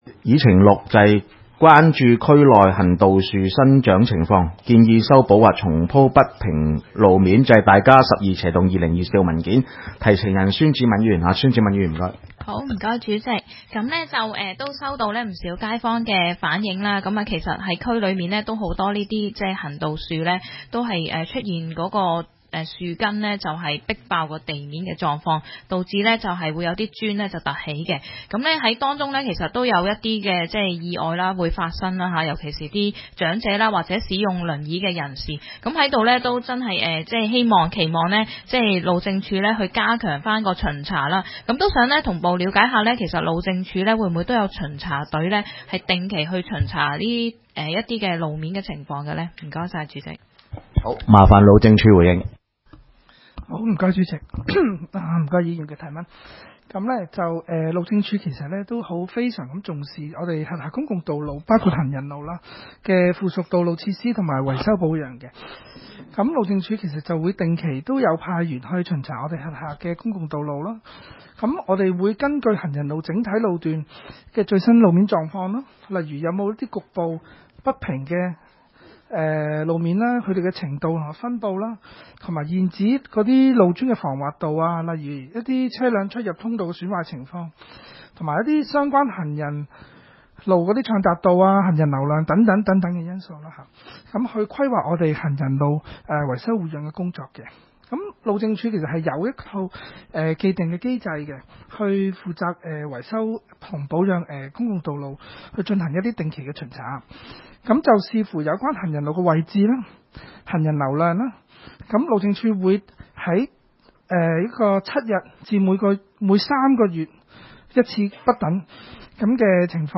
會議的錄音記錄